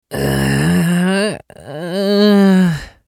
青年ボイス～日常ボイス～
☆★☆★日常系☆★☆★
【悩む(大)】